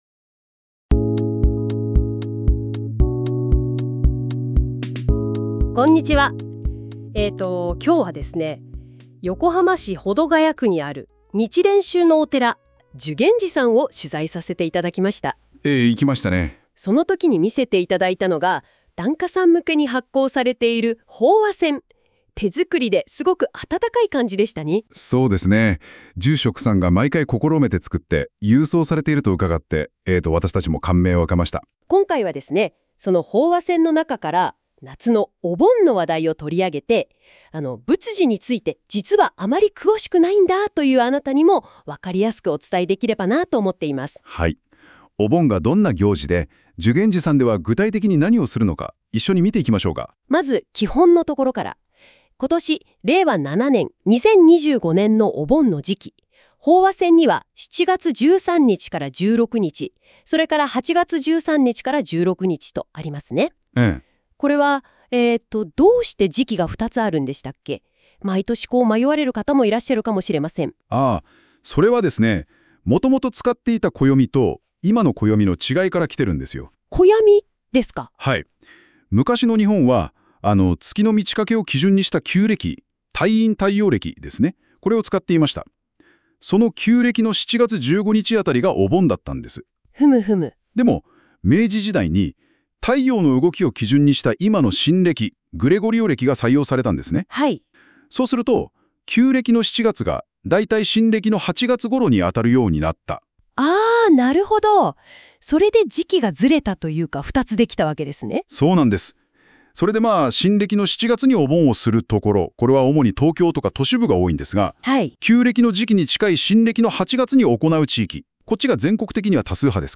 ＊この法話箋は音声ラジオ（Audio Overview）で解説しています（尺：6分49秒）。
注：音声はAIによる自動生成のため、一部読み方に間違いがあります。